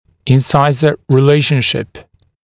[ɪn’saɪzə rɪ’leɪʃnʃɪp][ин’сайзэ ри’лэйшншип]соотношение резцов